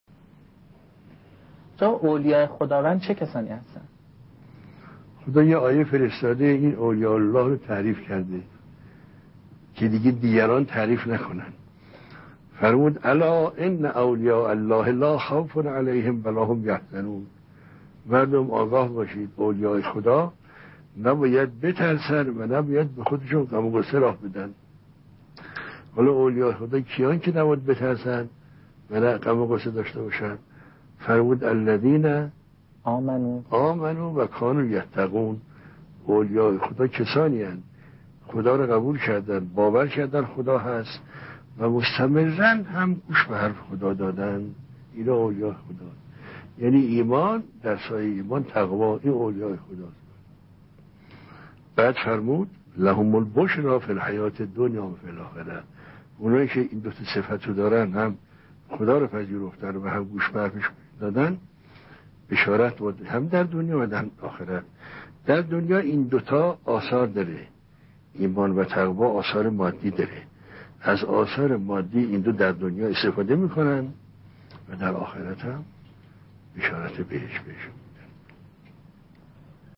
در یکی از دروس اخلاق خود